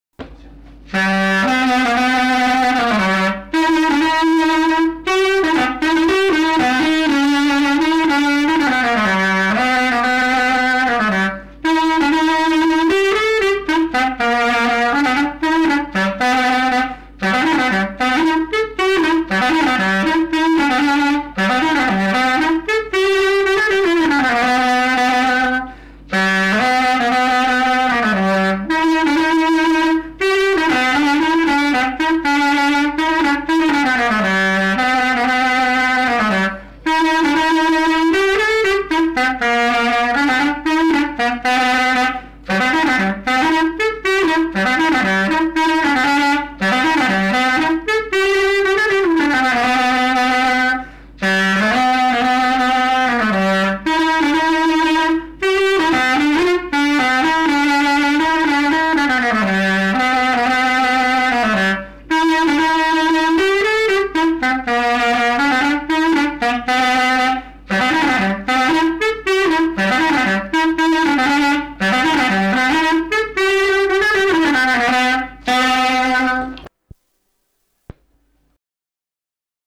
Mémoires et Patrimoines vivants - RaddO est une base de données d'archives iconographiques et sonores.
Marche nuptiale
Pièce musicale inédite